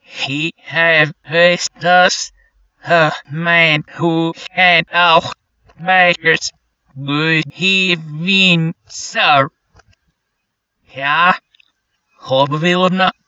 I said the entire strip's dialogue backwards in Audacity, and then reversed the audio. It sounds a little choppy, but I'm still amazed at how close I got in only one take.